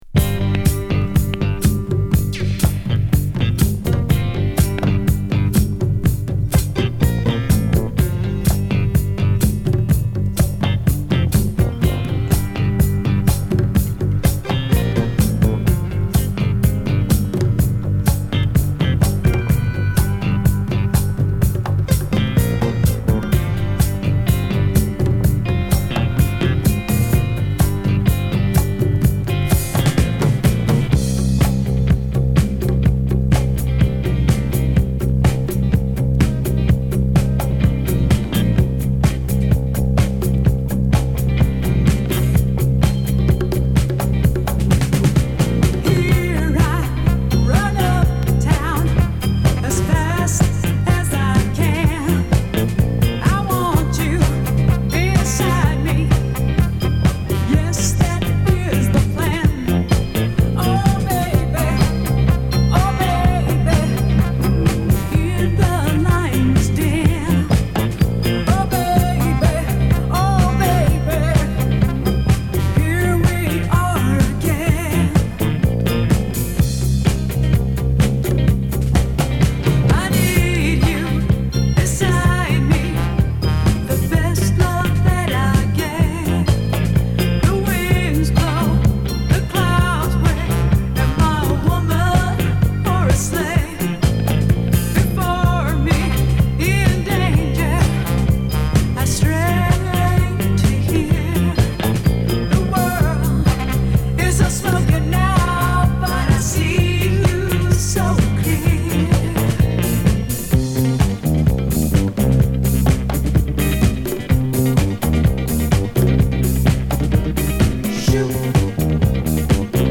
女性ボーカルとギターの掛け合いがとっても素敵なニューウェイブ・ディスコ・クラシック！！
＊試聴はA→B1→B2です。